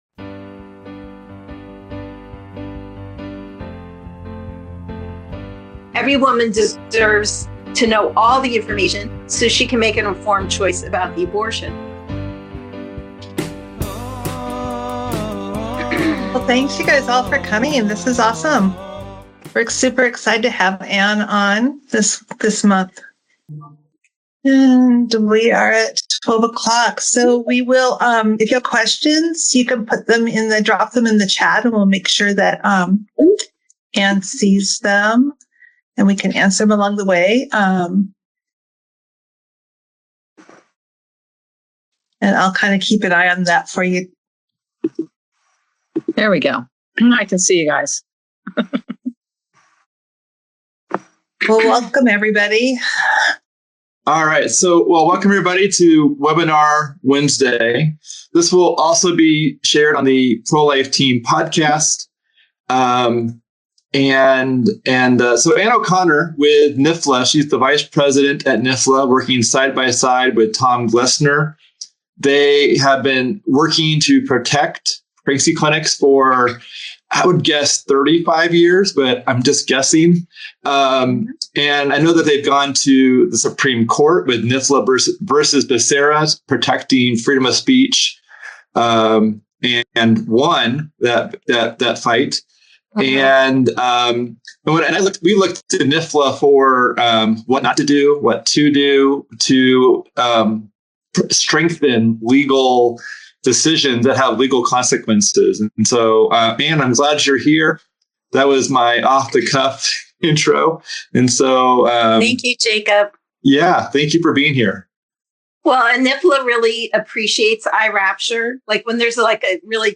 a live webinar with pregnancy clinic executive directors
Legal Protections for Pregnancy Centers with Live Q&A